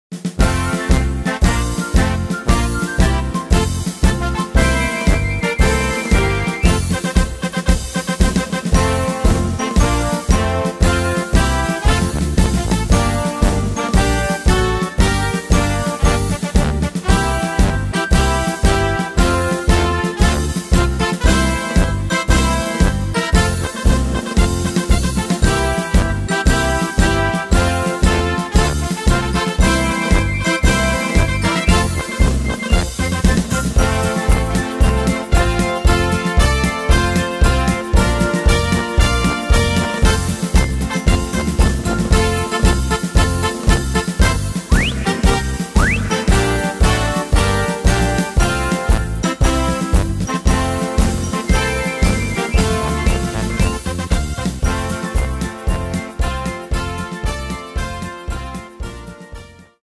Tempo: 115 / Tonart: F-Dur